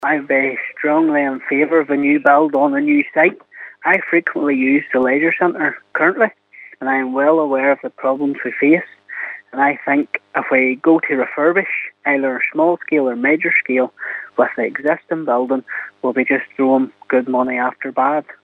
Councillor Timothy Gaston knows what he prefers: